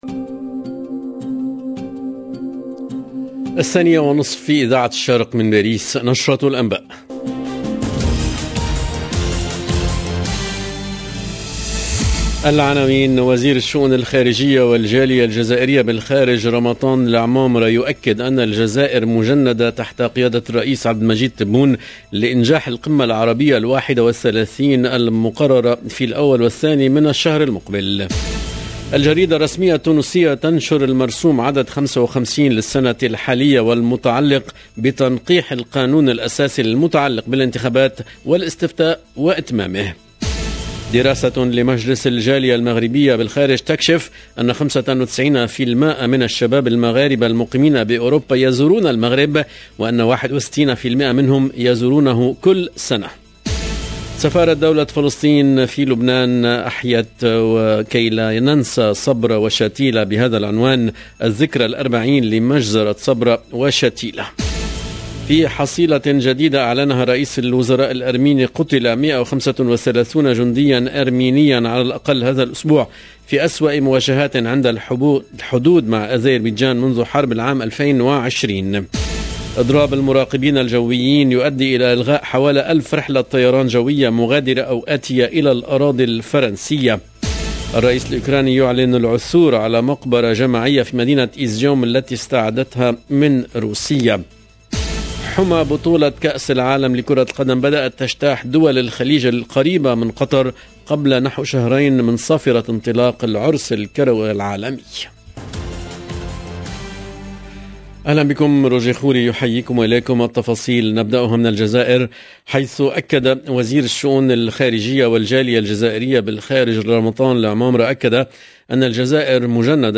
EDITION DU JOURNAL DE 14H30 EN LANGUE ARABE DU 16/9/2022